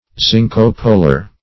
Search Result for " zinco-polar" : The Collaborative International Dictionary of English v.0.48: Zinco-polar \Zin`co-po"lar\, a. [Zinco- + polar.]